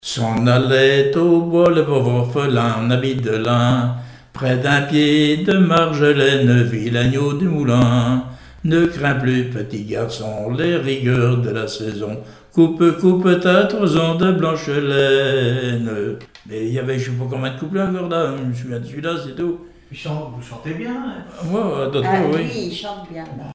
Genre brève
Témoignages et musiques
Pièce musicale inédite